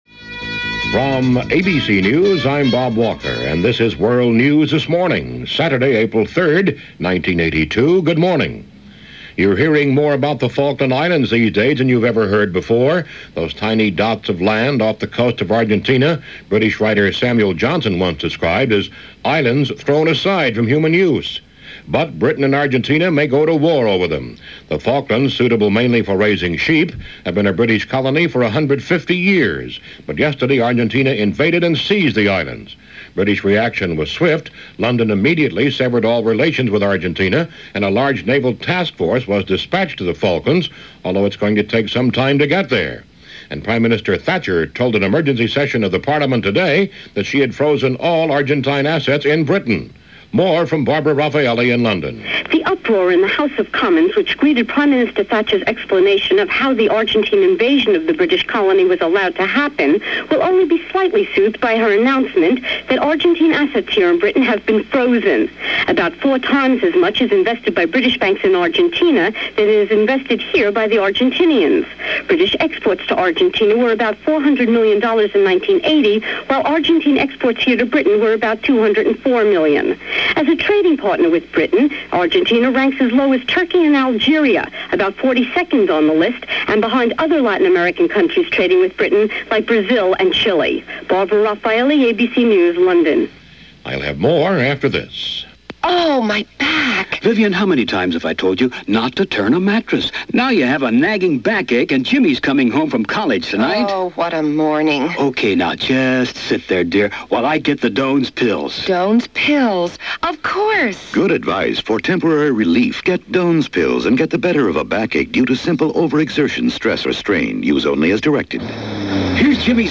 Along with a lot of other news via this edition of the ABC Information Network’s World News This Morning.